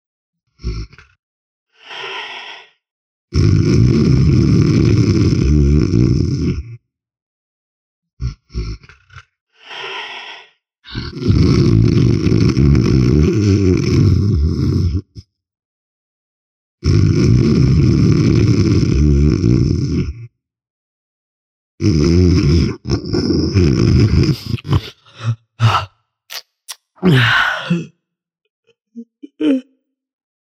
Звуки дыхания, храпа
Громкий мужской храп раздражает